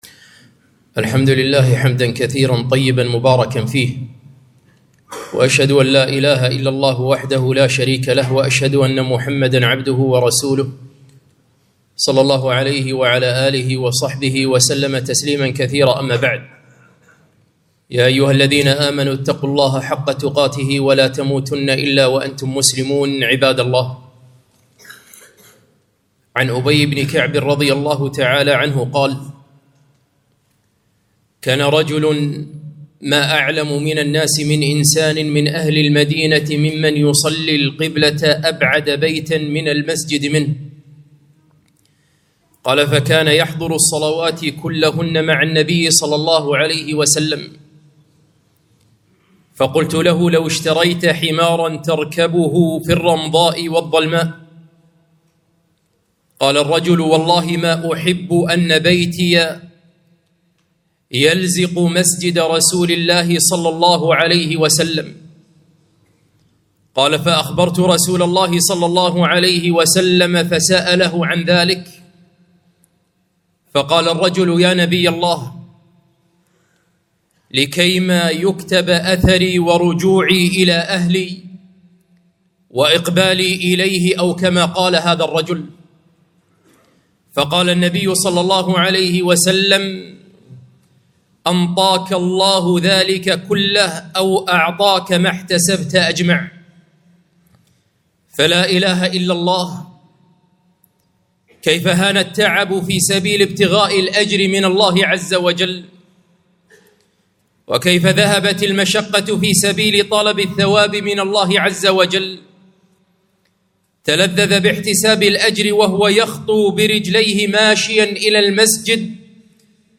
خطبة - خطوات إلى المسجد - دروس الكويت